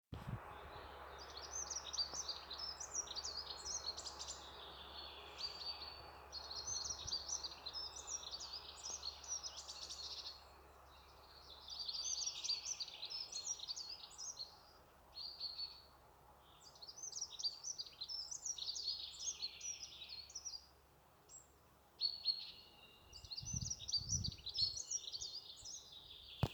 Dunnock, Prunella modularis
StatusSinging male in breeding season